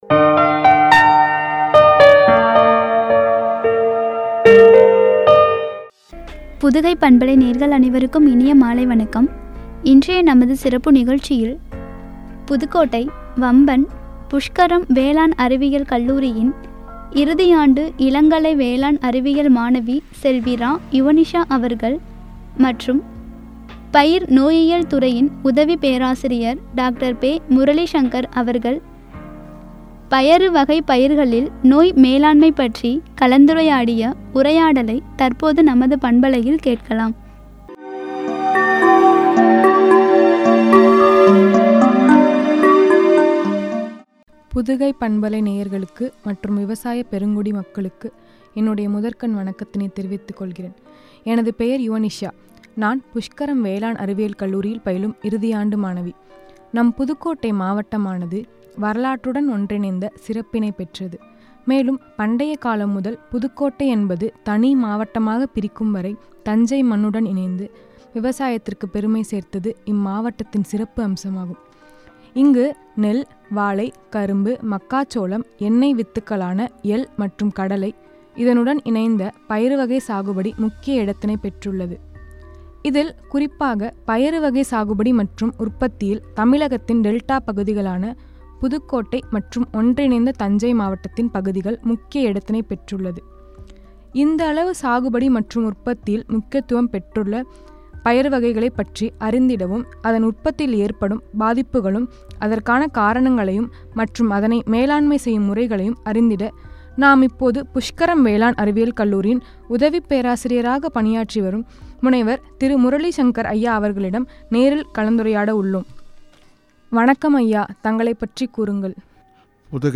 கலந்துரையாடல்.